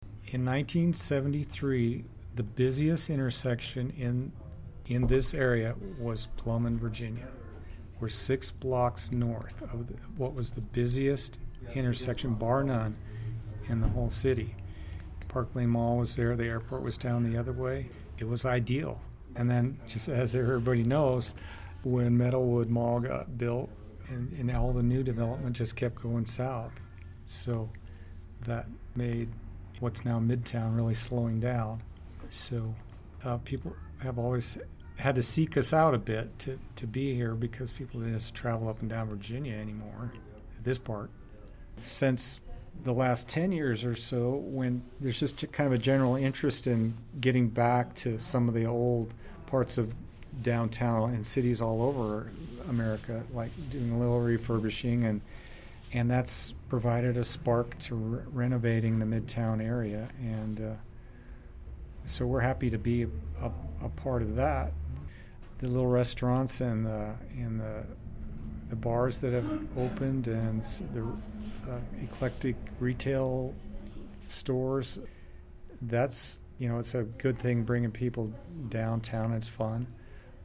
Interviewed in 2015